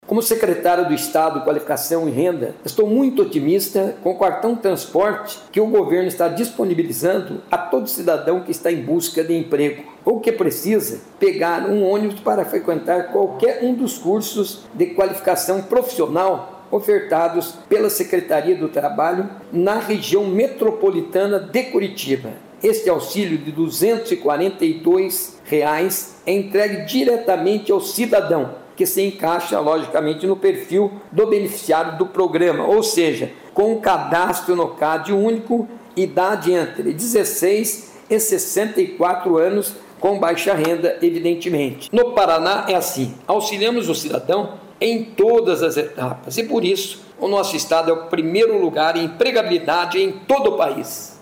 Sonora do secretário do Trabalho, Qualificação e Renda, Mauro Moraes, sobre o cadastro de usuários para o Cartão Social do Estado